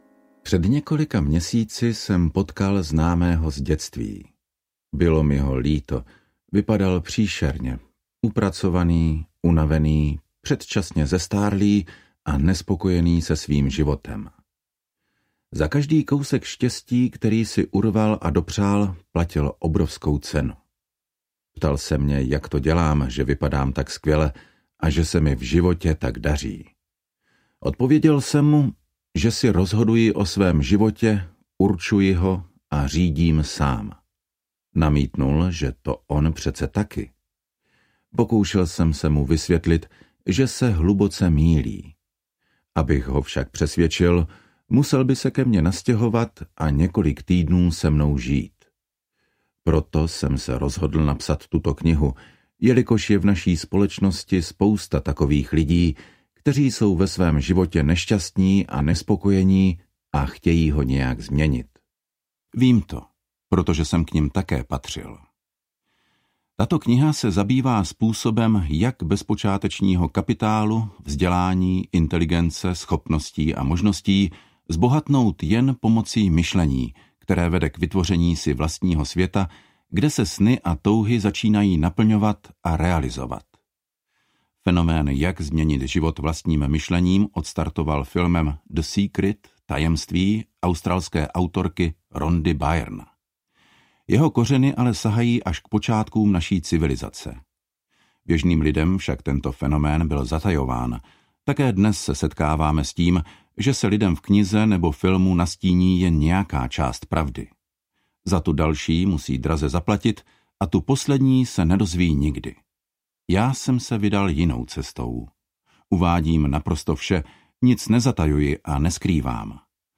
Indigový svět audiokniha
Ukázka z knihy